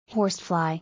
[ˈhɔ:nɪt]